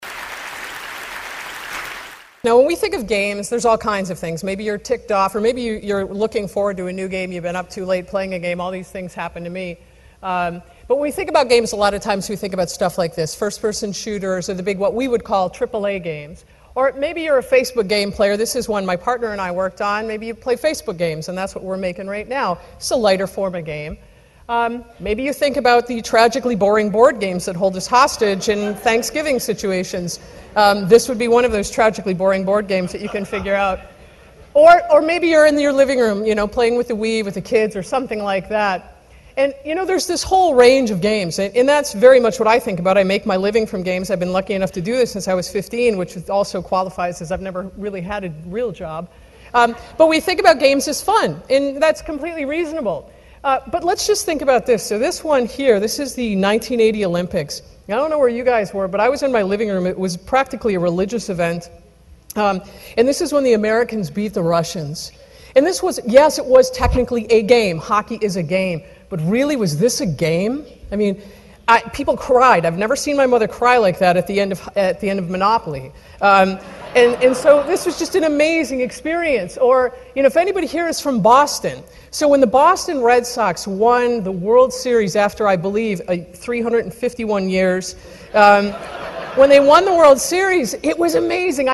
TED演讲:为了理解而游戏(1) 听力文件下载—在线英语听力室